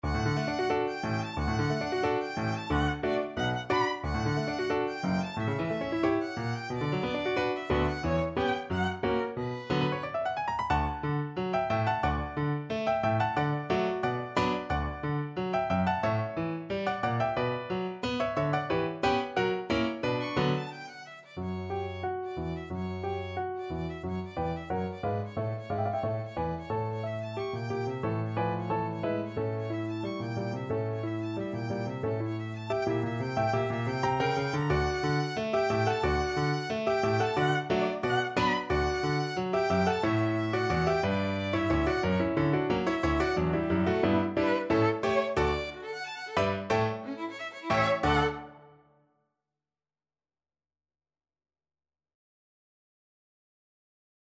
2/4 (View more 2/4 Music)
Violin  (View more Intermediate Violin Music)
Classical (View more Classical Violin Music)